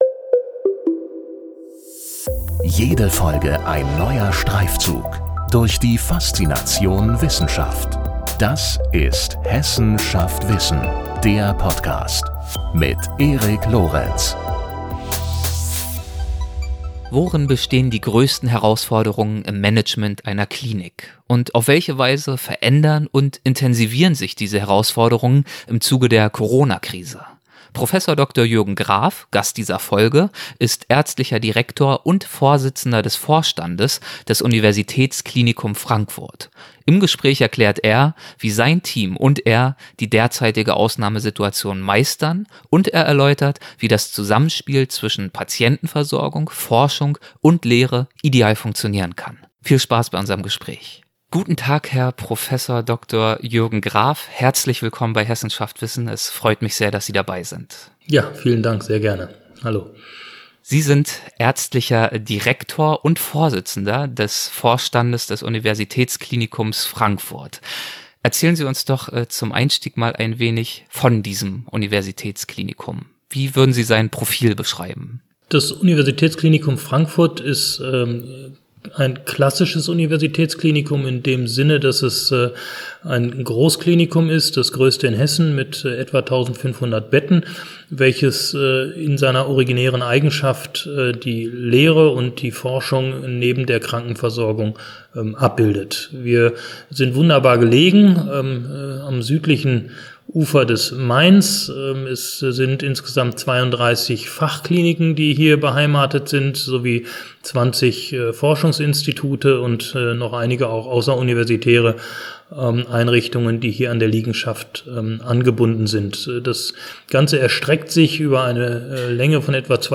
Im Gespräch erklärt er, wie sein Team und er die derzeitige Ausnahmesituation meistern, und er erläutert, wie das Zusammenspiel zwischen Patientenversorgung, Forschung und Lehre ideal funktionieren kann.